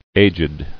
[ag·ed]